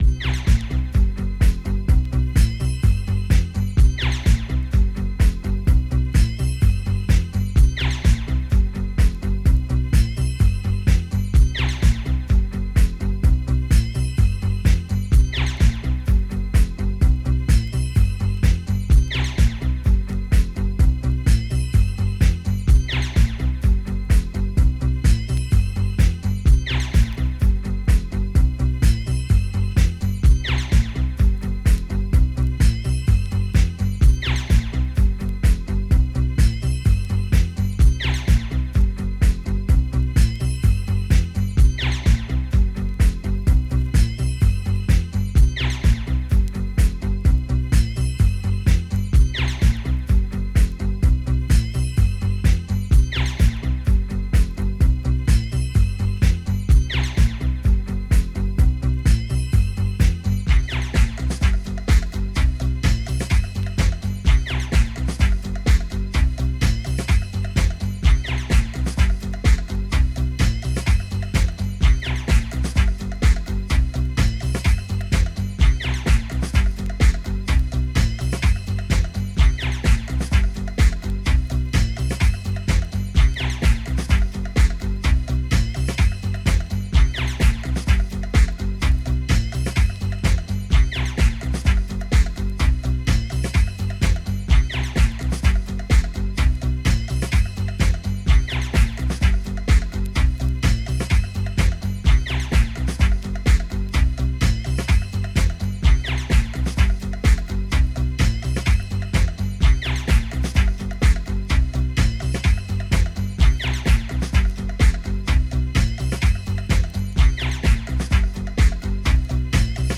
classic Chicago house